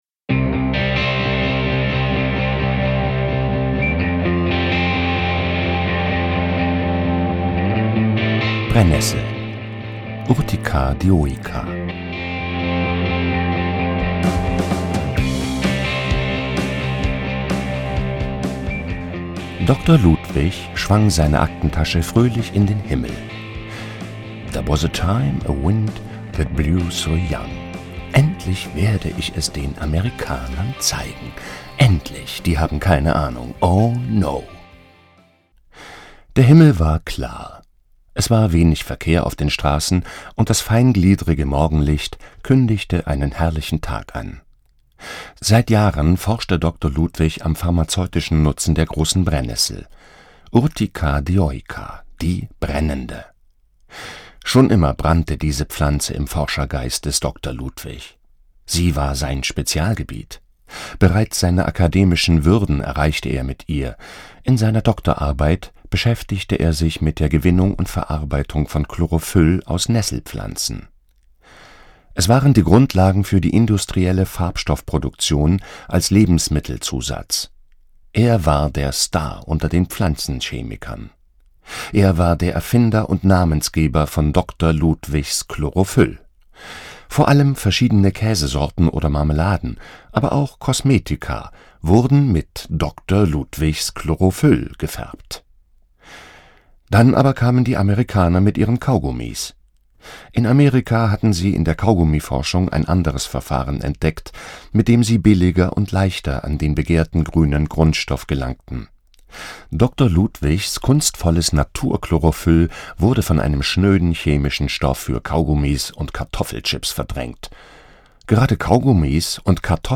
jederzeit auf eigene Faust: HÖRSPAZIERGANG HORTUS TOXICUS